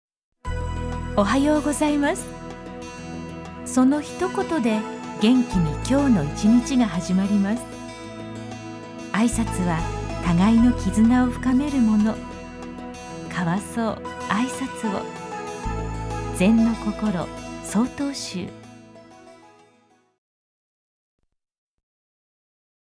ラジオ放送コマーシャル（mp3ファイル）